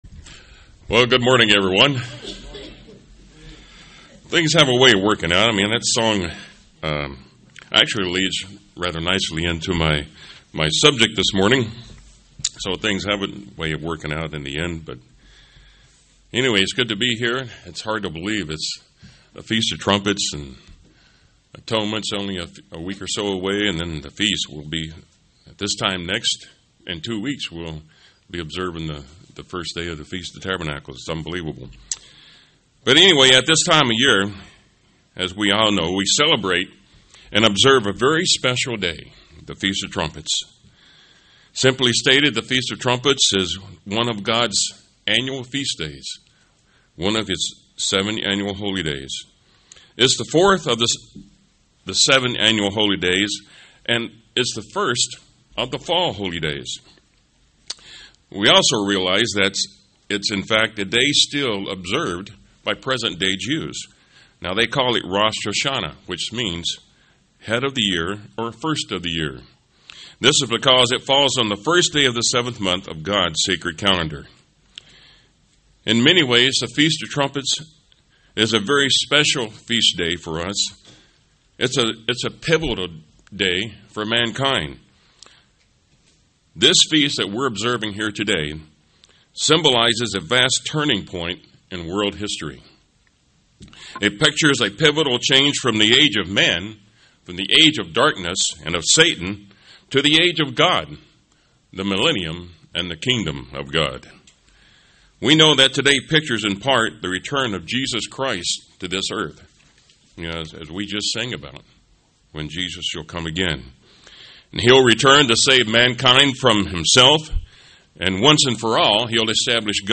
Given in St. Petersburg, FL
Feast of Trumpets Studying the bible?